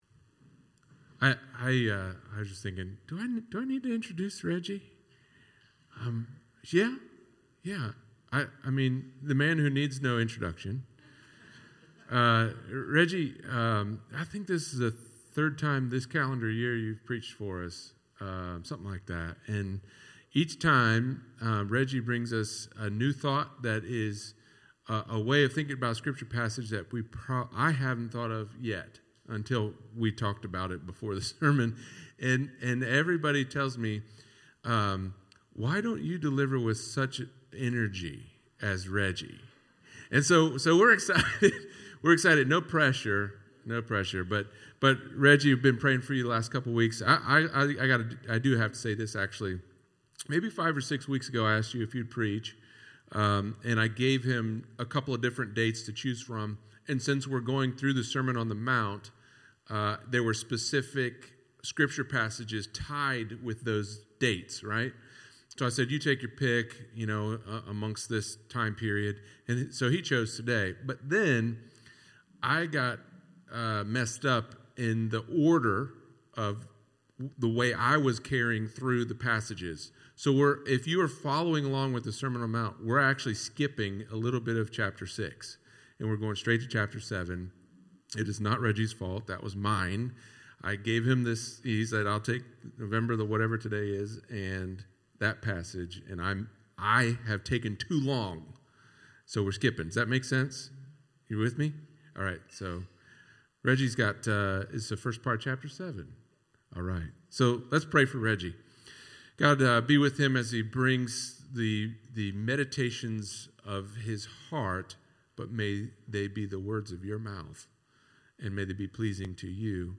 sermon-on-the-mount-judge-not-lest-you-be-judged.mp3